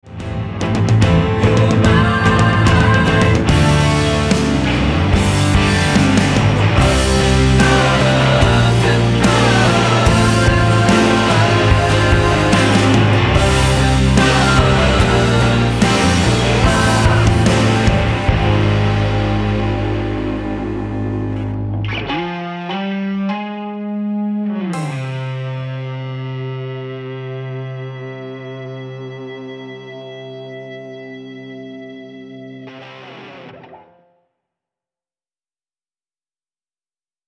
--> MP3 Demo abspielen...
Tonart:Ab mit Chor